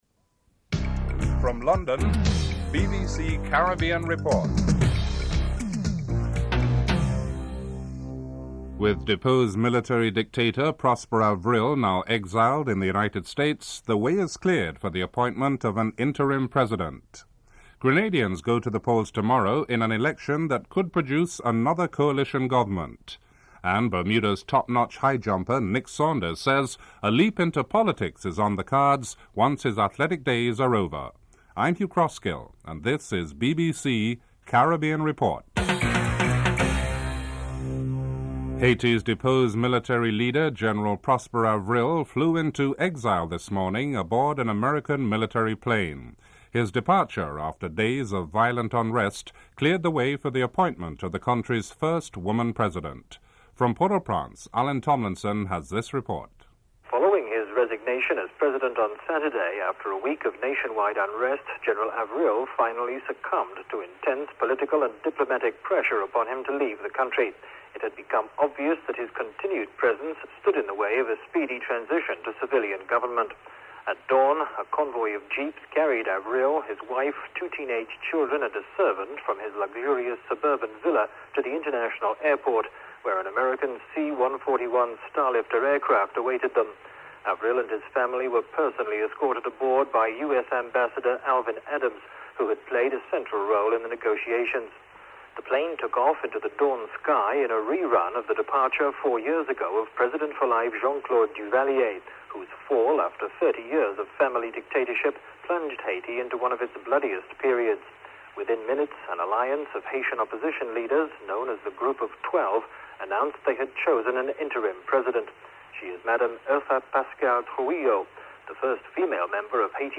Includes a musical interlude at the beginning of the report.